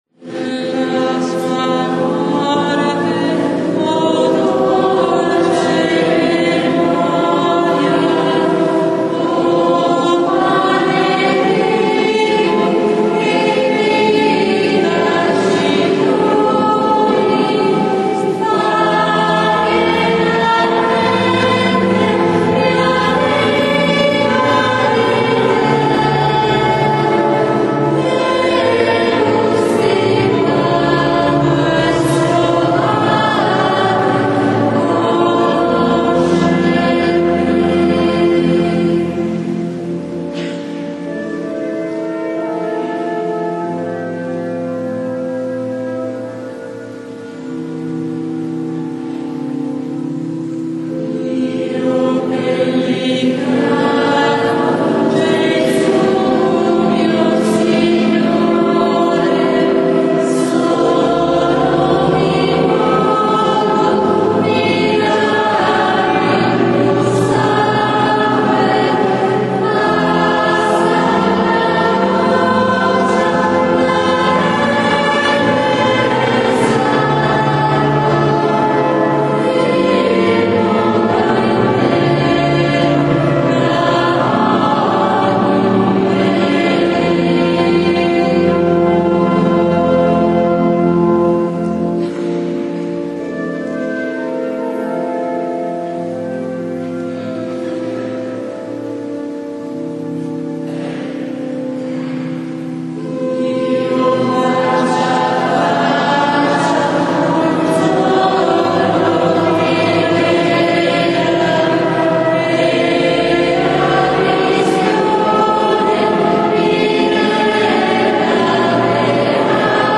GIOVEDI SANTO -Celebrazione della Cena del Signore-
canti: